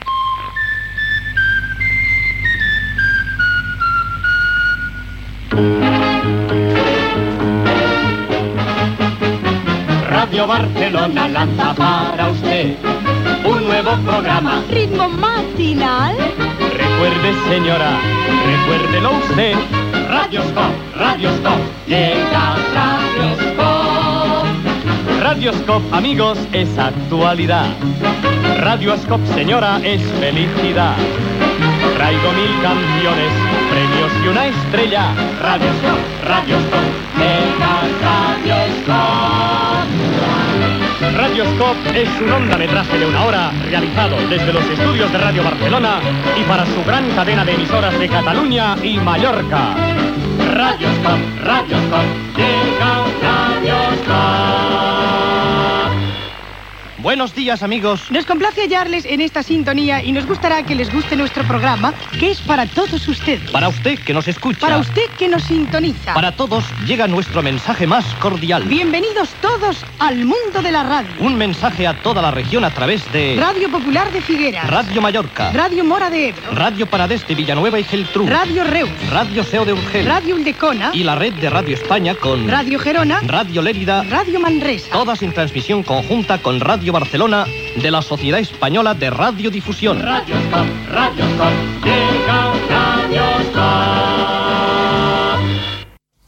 Careta cantada del programa i emissores que l'emeten.
Entreteniment